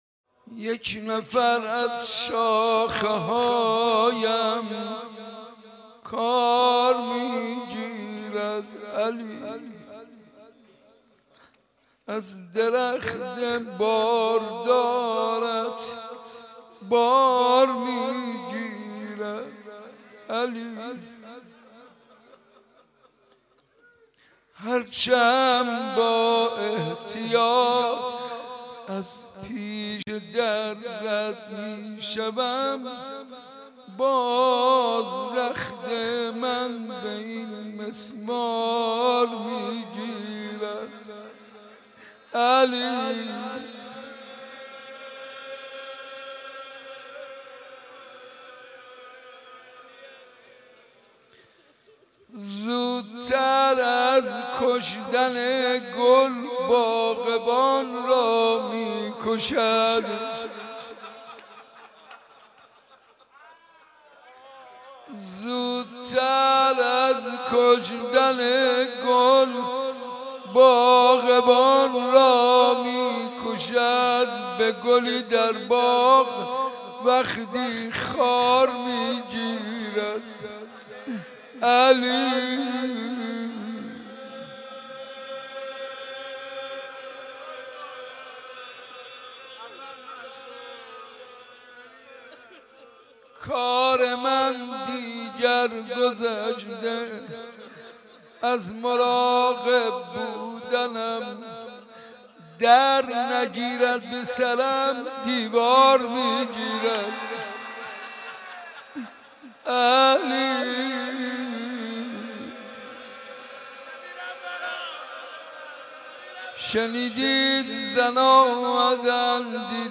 مراسم زیارت عاشورا روز چهارم فاطمیه اول حسینیه صنف لباس فروشان ساعت 6 صبح با سخنرانی حجت الاسلام و المسلمین